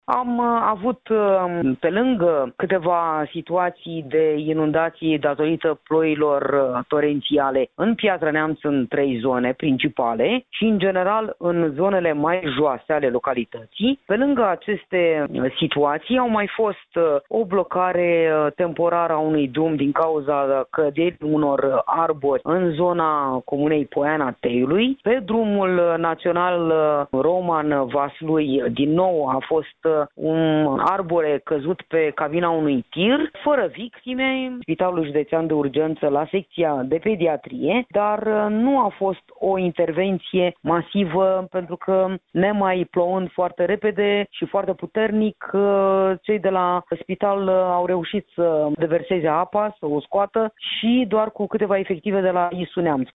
În acest moment, în judeţ nu mai sunt probleme, după cum a declarat prefectul Daniela Soroceanu: